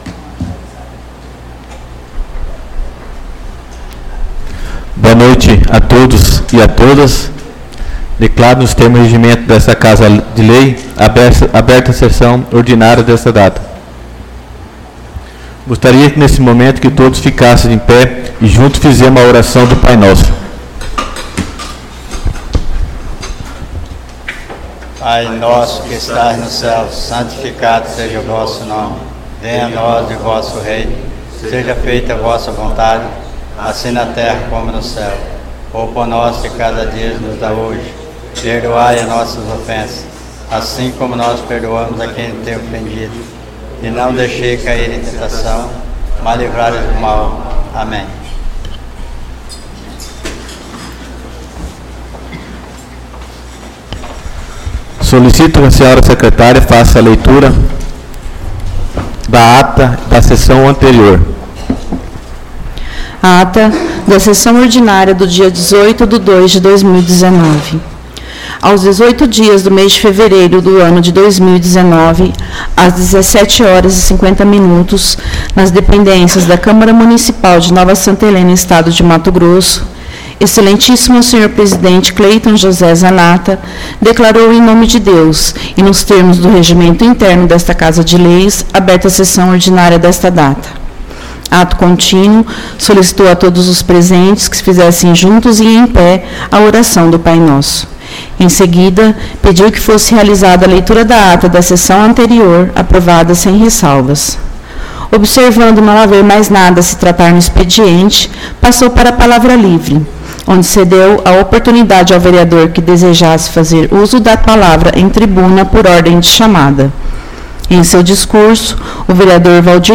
Áudio da Sessão Ordinária 12/03/2019